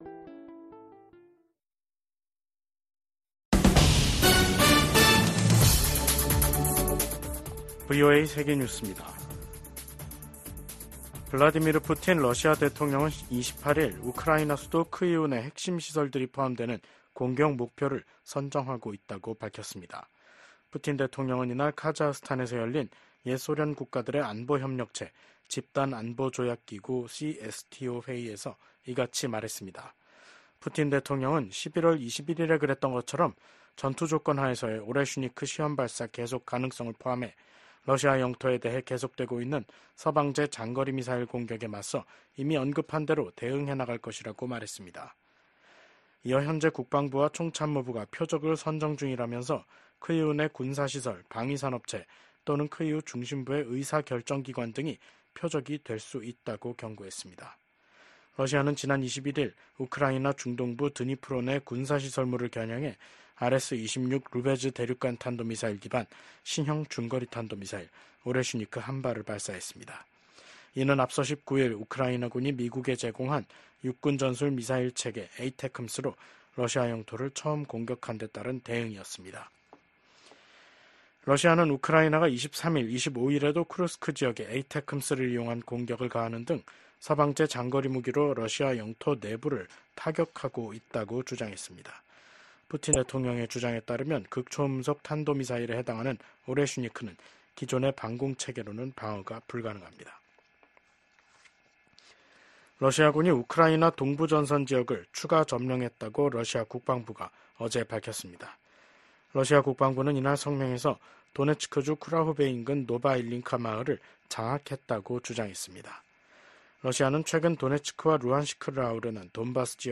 VOA 한국어 간판 뉴스 프로그램 '뉴스 투데이', 2024년 11월 28일 3부 방송입니다. 우크라이나 사태를 주제로 열린 유엔 안보리 회의에서 미국 대표는 북한을 향해 러시아 파병이 사실이냐고 단도직입적으로 물었고, 북한 대표는 북러 조약 의무를 충실히 이행하고 있다며 이를 우회적으로 시인했습니다. 러시아와 전쟁 중인 우크라이나 특사단이 윤석열 한국 대통령 등을 만났지만 한국 측은 무기 지원에 신중한 입장을 보였습니다.